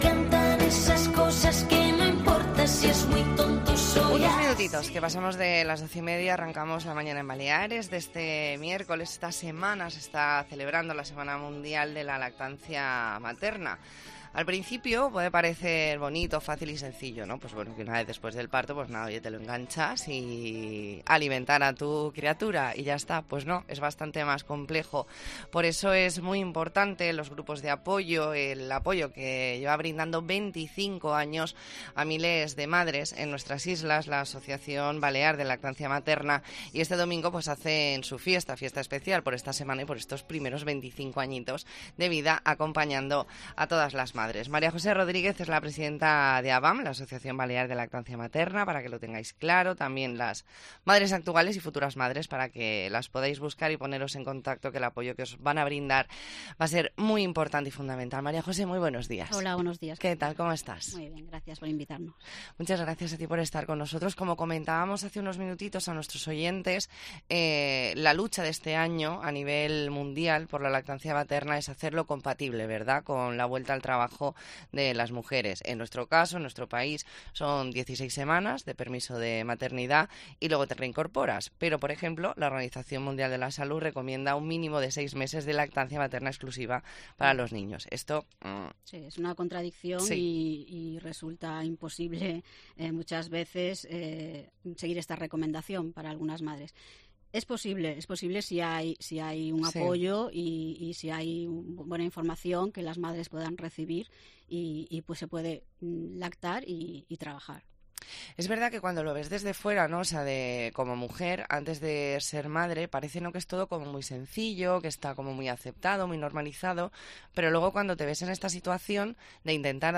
Entrevista en La Mañana en COPE Más Mallorca, miércoles 27 de septiembre de 2023.